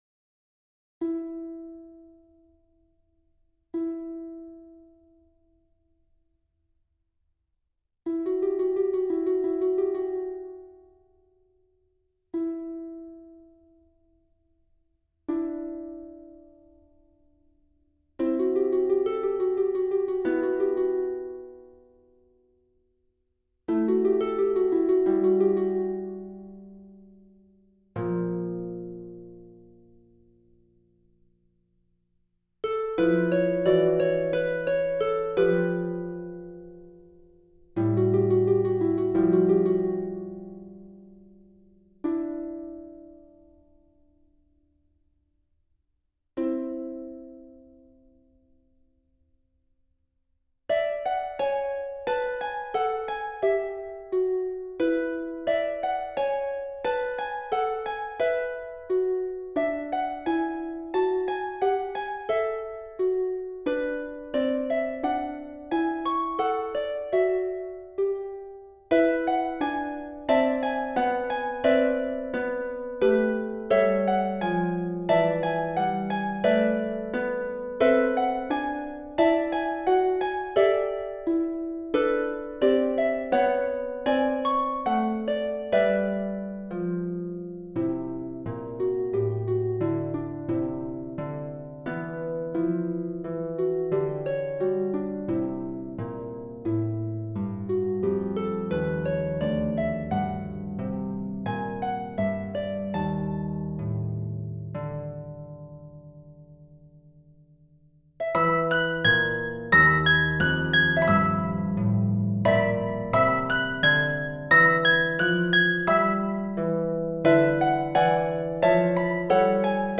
Composed in 2020 for Solo Lever Harp
One movement